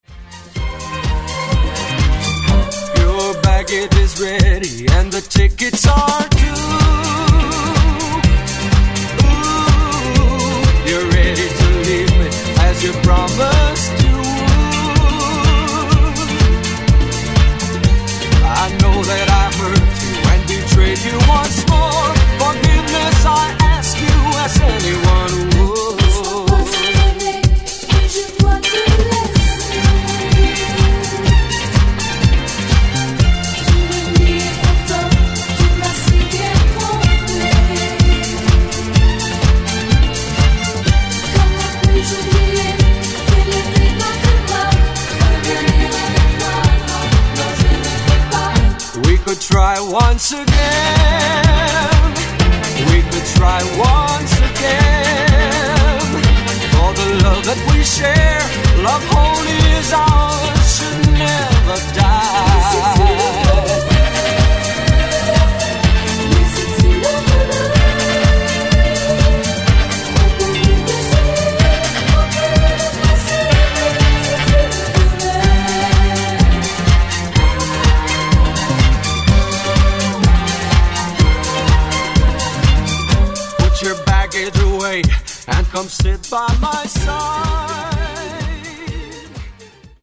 Genre: 2000's Version: Clean BPM